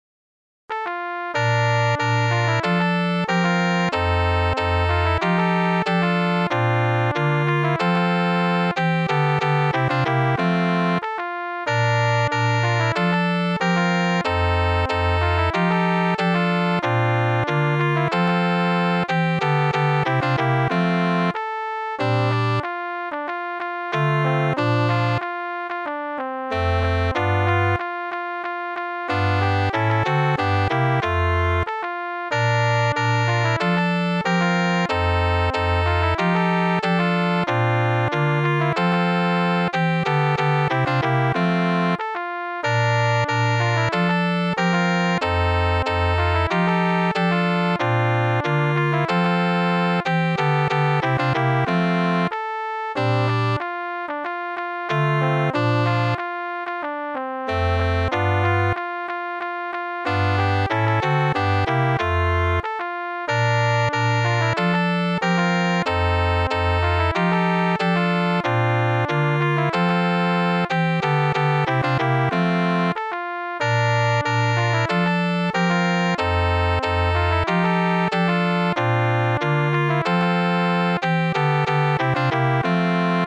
SATB (4 voces Coro mixto )
Soprano (1) (1 solista(s) )
Tonalidad : fa mayor